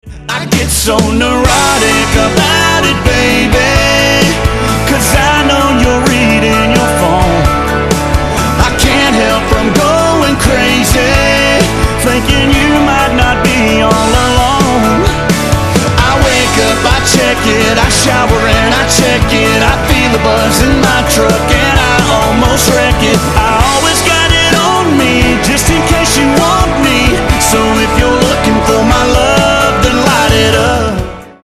• Качество: 128, Stereo
красивый мужской вокал
легкий рок
кантри
Фрагмент душевной песни про любовь